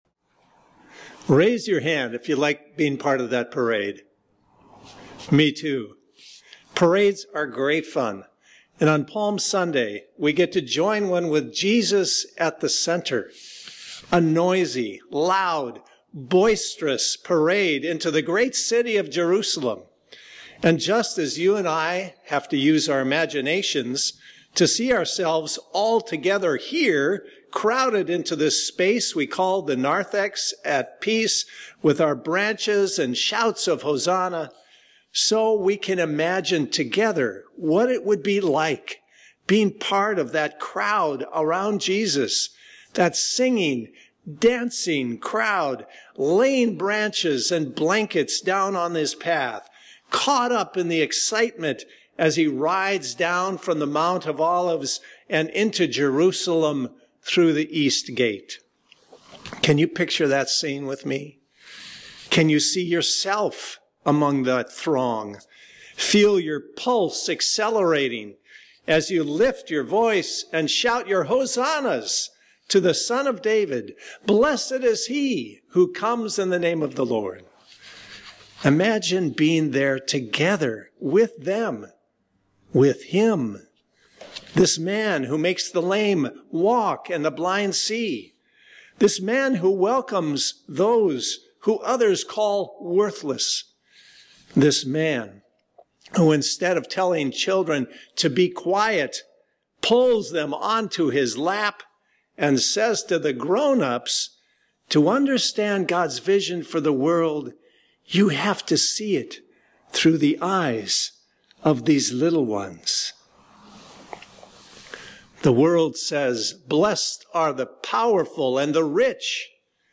Palm Sunday message
Peace Cantata sung by the Peace Lutheran Church Choir Listen to an audio-only recording of my message here: Lent Palm Sunday recorded message 4-5-2020Audio